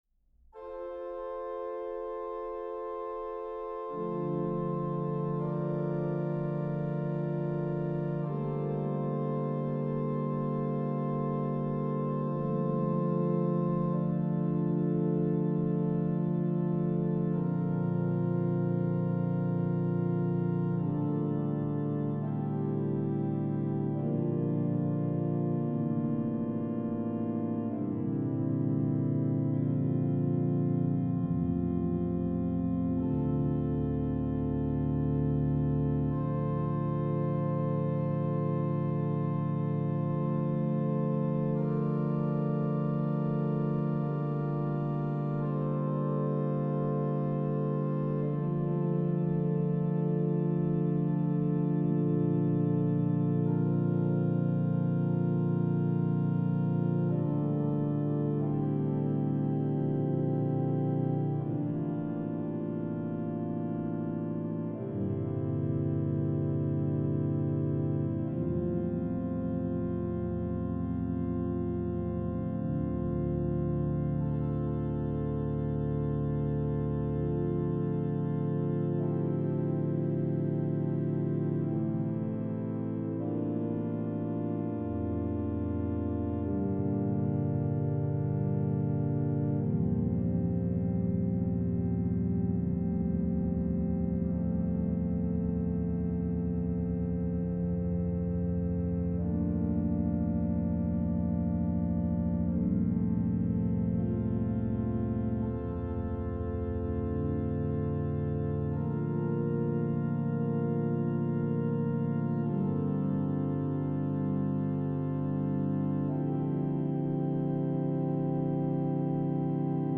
organ Click to listen.